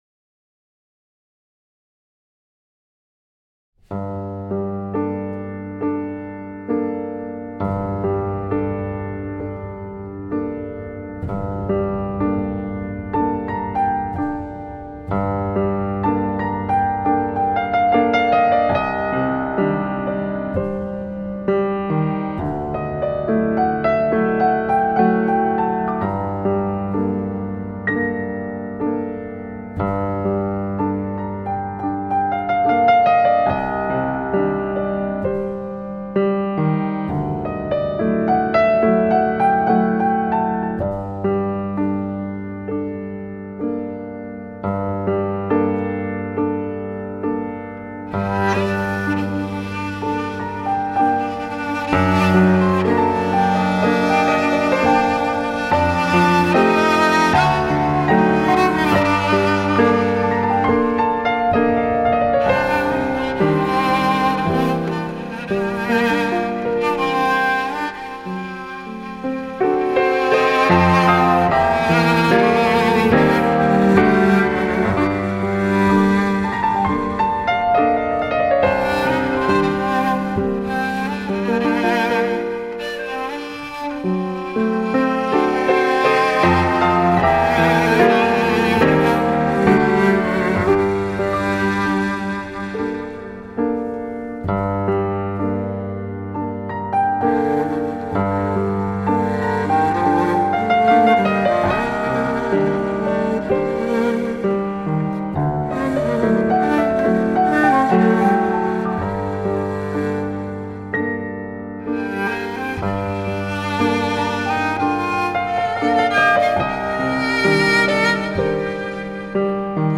کمانچه آلتو
پیانو
این قطعه براساس یک ملودی ارمنی ساخته شده است.